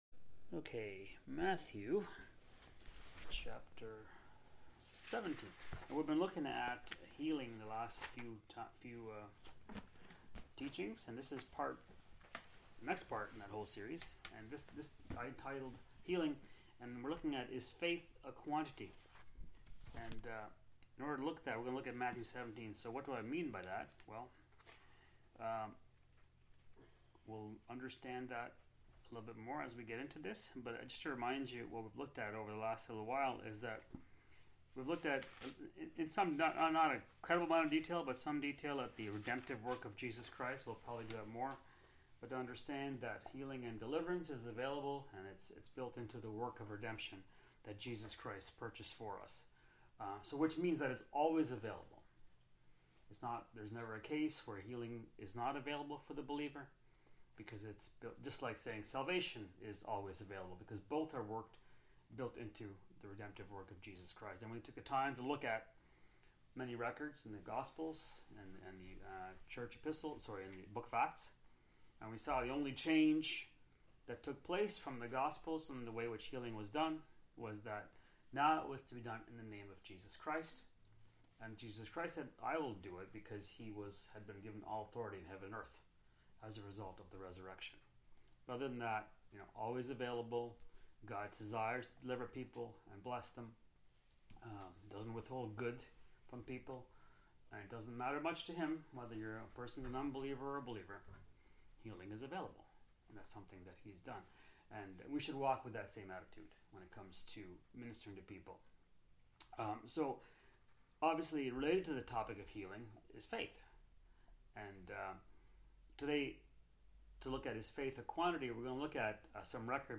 This teaching considers whether faith can be measured or not.